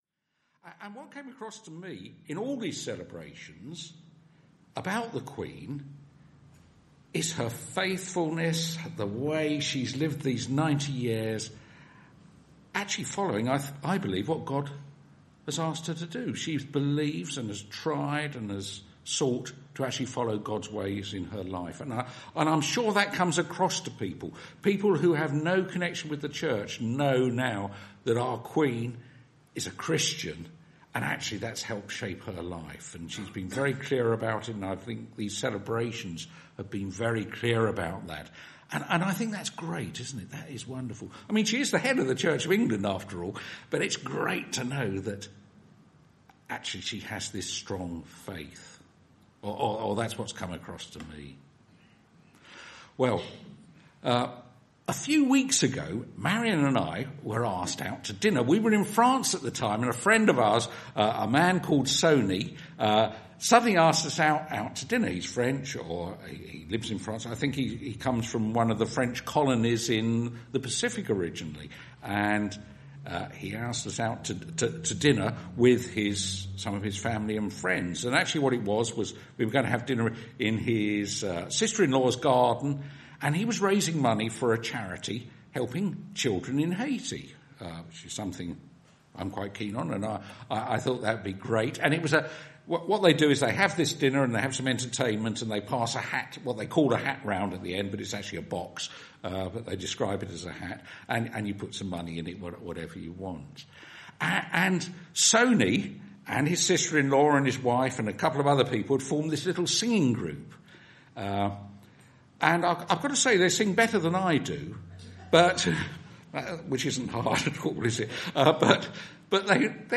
2016 May-Aug Audio Sermons — All Saints' Church Laleham